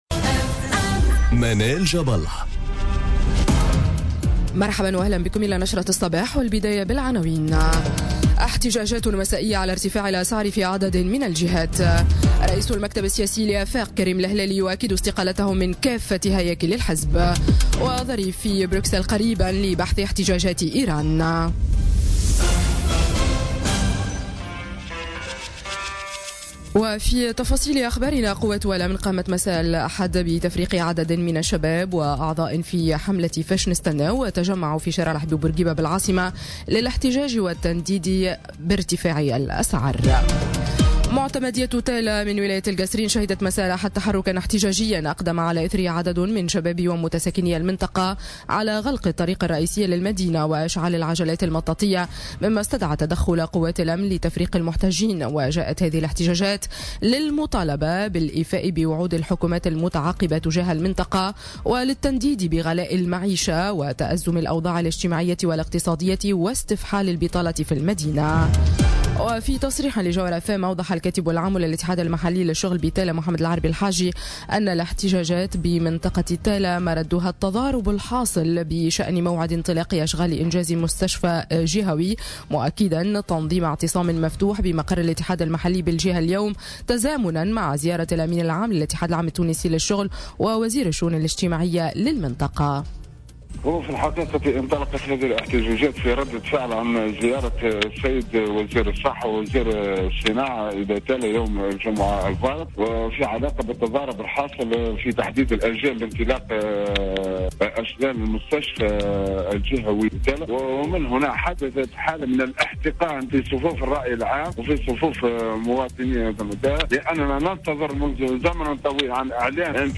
نشرة أخبار السابعة صباحا ليوم الإثنين 8 جانفي 2018